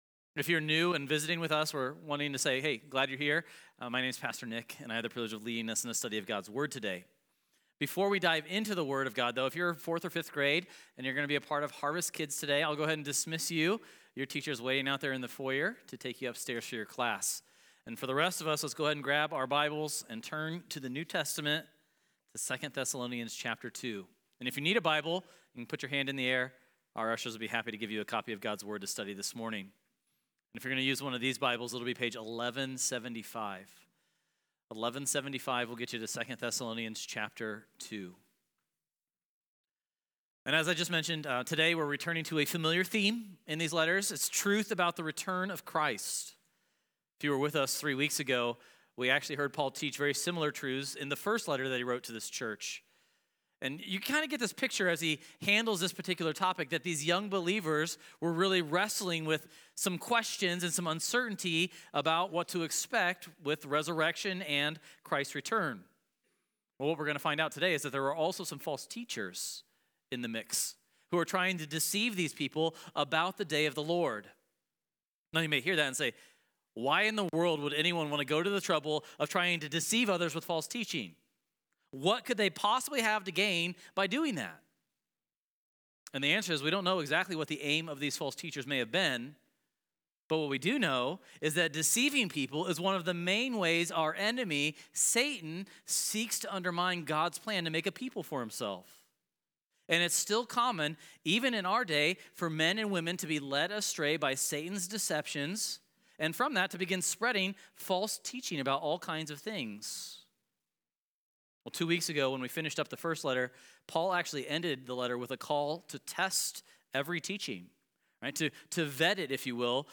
Good morning church family! (Introduce self + welcome guests) Dismiss 4 th + 5 th graders Ushers + Bibles (2 Thessalonians 2; page 1175) Today we return to a familiar theme in our study of Thessalonians, truth about the return of Christ.